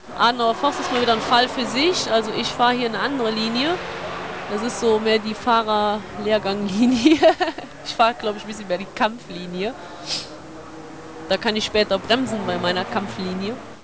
Sabine comments on a lap of the nr.44 Nissan Skyline